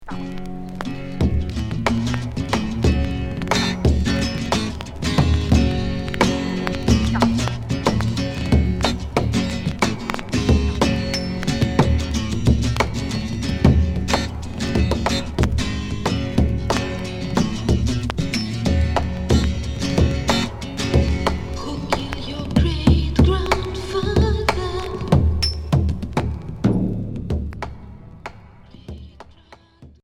Progressif Franco-grec